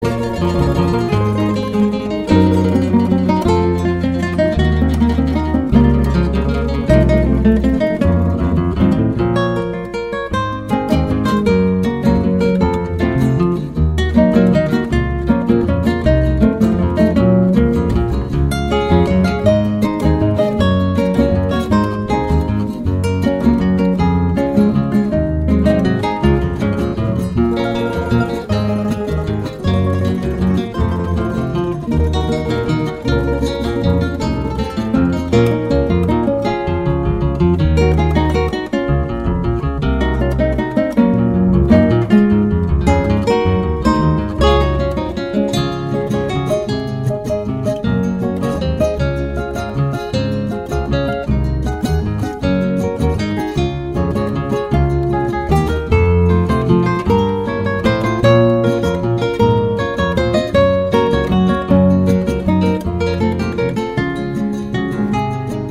música académica costarricense